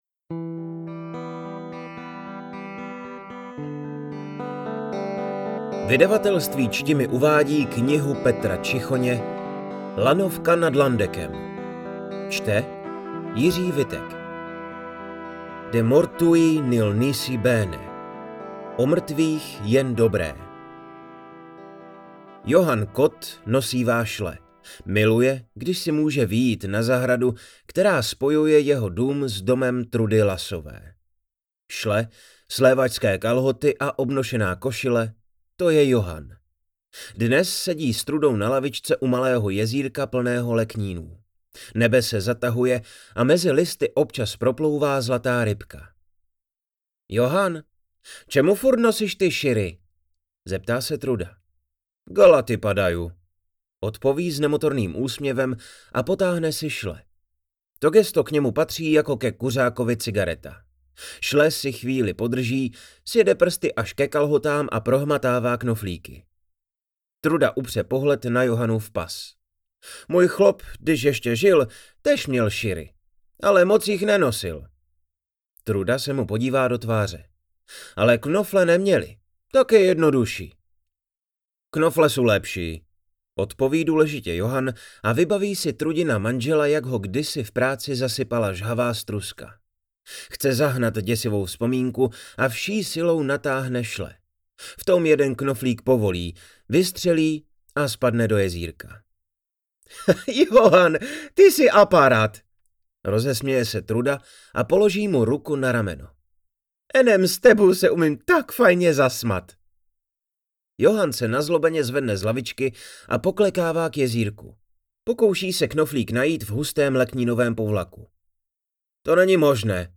AudioKniha ke stažení, 47 x mp3, délka 4 hod. 18 min., velikost 234,5 MB, česky